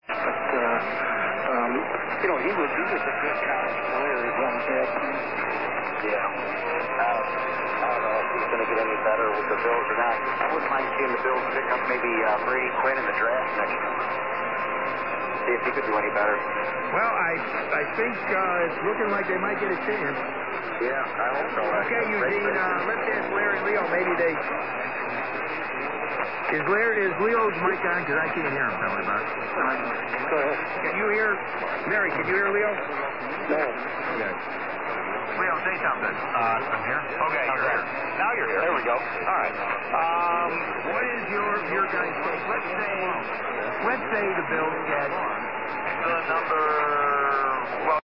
Re: [IRCA] Tentative log of WJJG at signoff here in Eastern MA